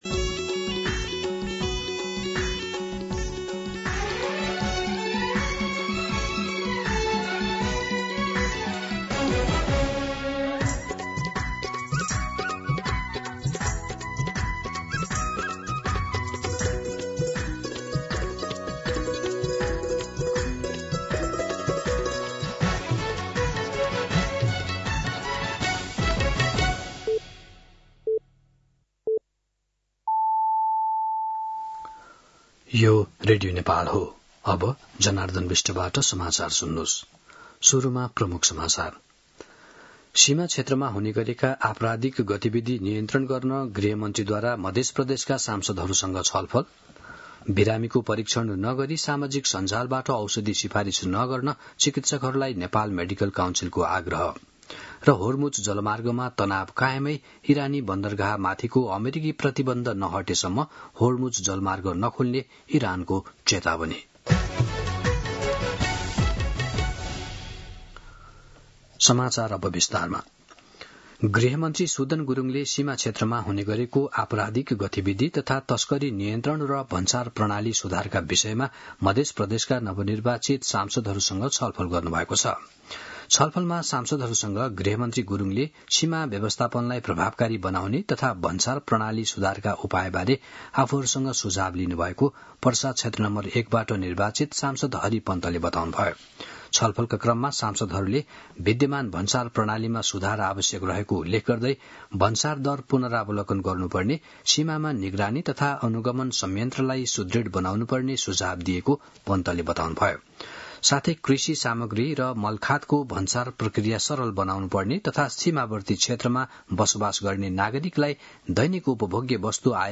दिउँसो ३ बजेको नेपाली समाचार : ५ वैशाख , २०८३